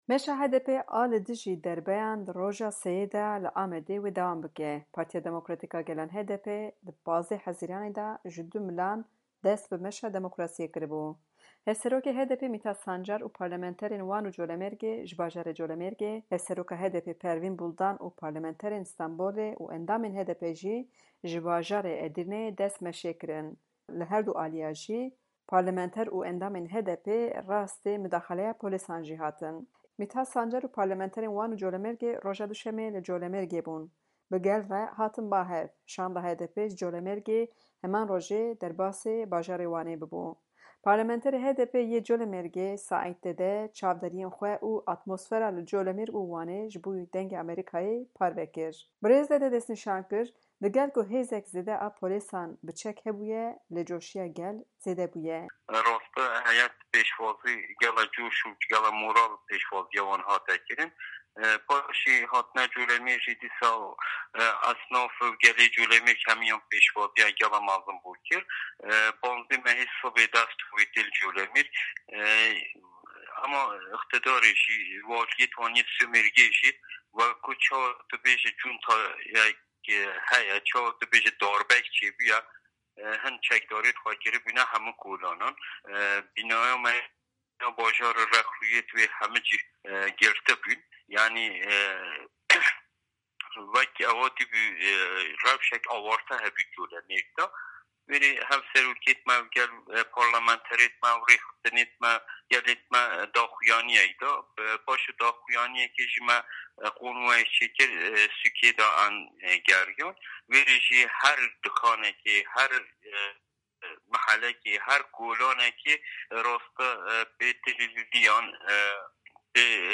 Saît Dede, parlamenterê HDPê yê Colemêrgê ji Dengê Amerîka re got ku ji mêj ve ye gel ji qadan hatibû vekişandin, lê ev meş bû çirûskek.